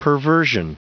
Prononciation du mot perversion en anglais (fichier audio)
Prononciation du mot : perversion